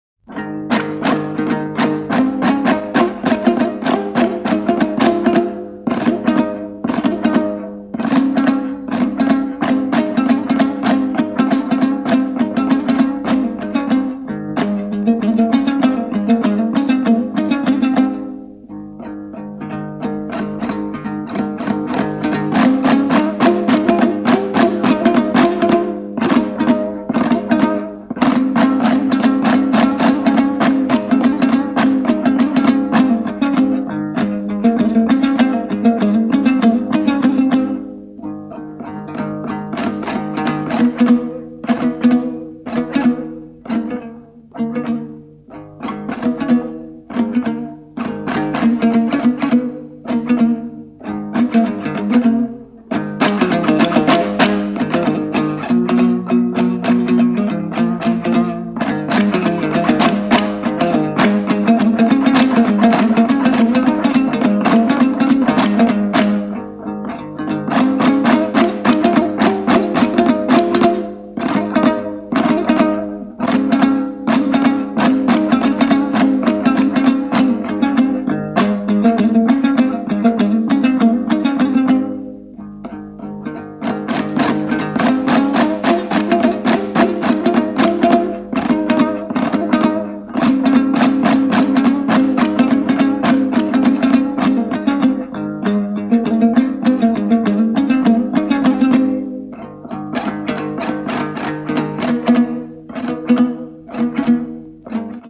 カザフスタンの伝統楽器、ドンブラの決定盤！素朴で暖かな哀音を帯びた音色が魅力的！